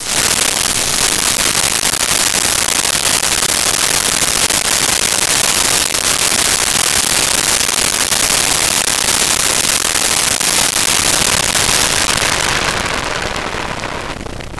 Fireworks Spinner 01
Fireworks Spinner 01.wav